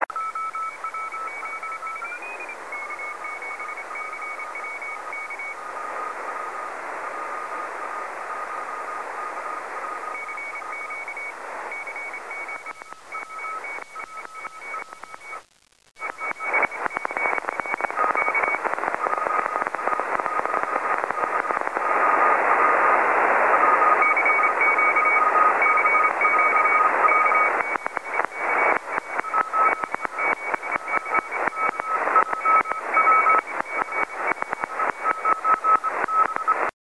6m1(MP3 361KB)　　 CW の交信の様子。ワイドで受信していますので、CQを出す局と交信している局両方が聞こえます。